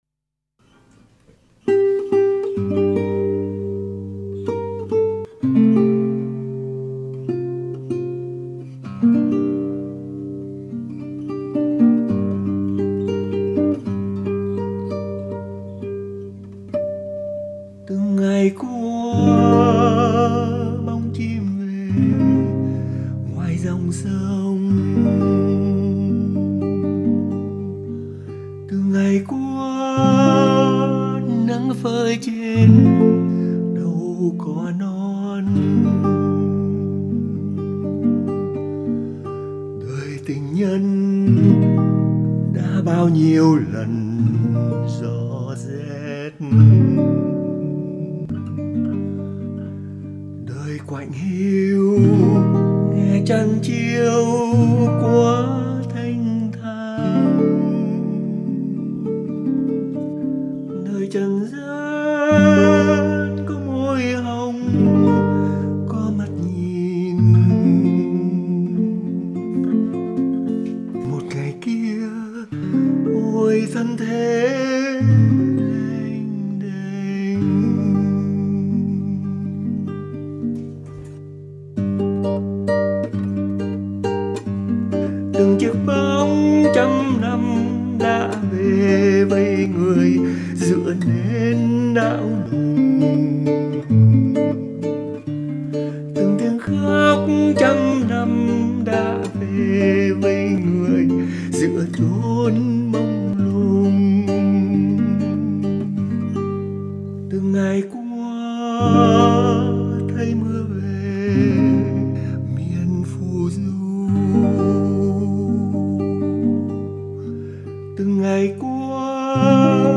đàn và hát.